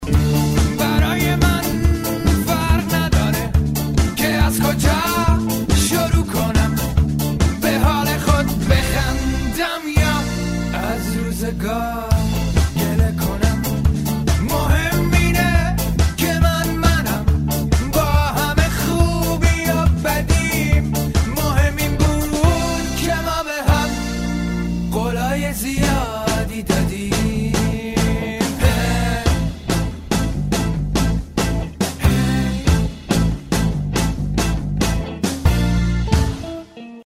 دانلود زنگ موبایل شاد برای صدای زنگ گوشی